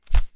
paperRustle1.wav